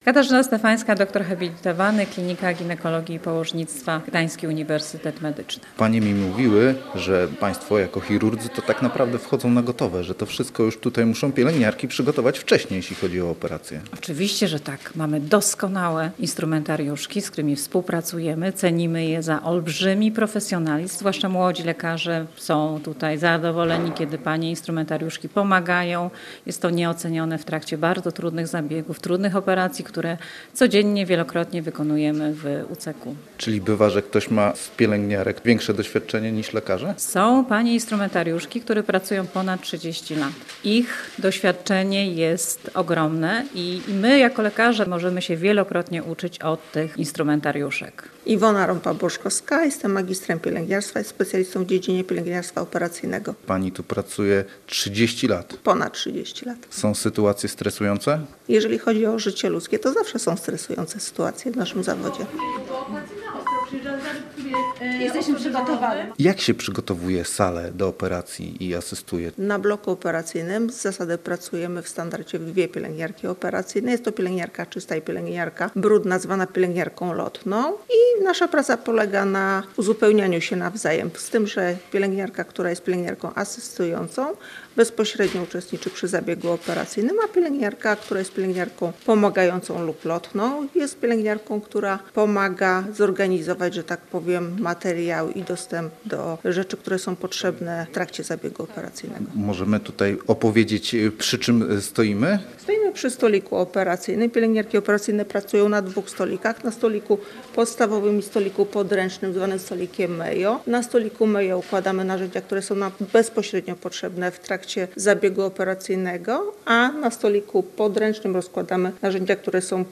Odkażony i przebrany w ochronny strój reporter mógł wejść na blok operacyjny z mikrofonem, żeby nagrać wypowiedzi pań, od których w dużej mierze zależy udany przebieg operacji. Jak mówiły, praca, w której jest się odpowiedzialnym za ludzkie zdrowie, a nawet życie, zawsze bywa stresująca.